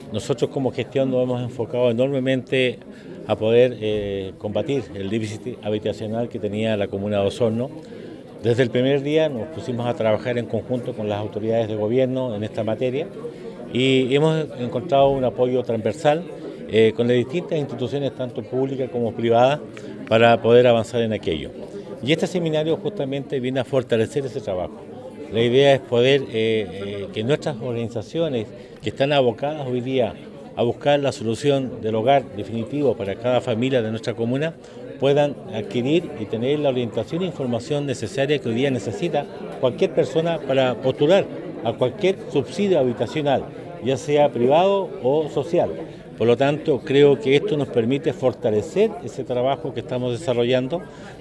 Del mismo modo el Alcalde Emeterio Carrillo, indicó que el déficit habitacional de Osorno es una preocupación vigente para las autoridades, por lo que esta instancia permitirá entregar la orientación necesarias para la postulación a subsidios habitacionales.